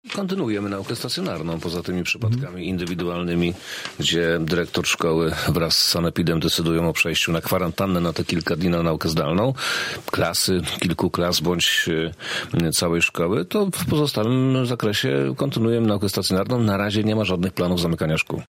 Przemysław Czarnek powiedział w Radiu Zachód, że na razie epidemia powoduje konieczność interwencji jedynie w jednostkowych przypadkach.
poranny-gosc-czarnek-o-edukacji.mp3